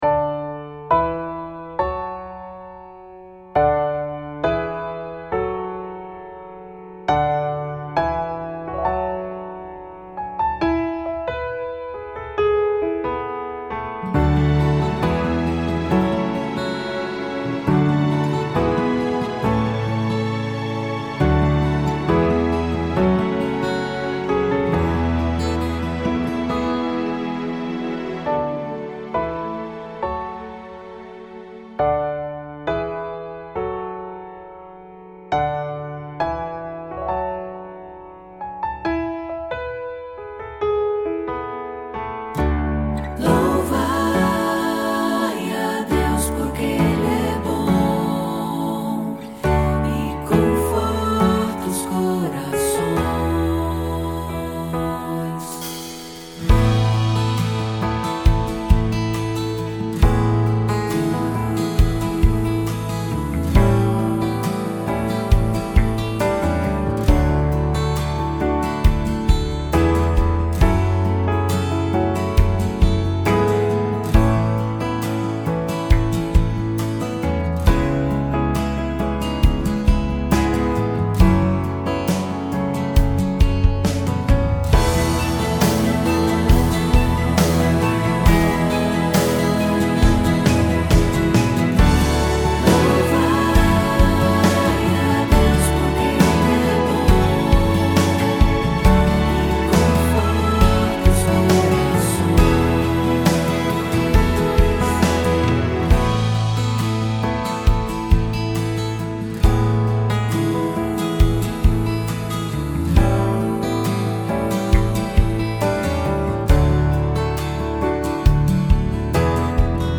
PlayBack (música)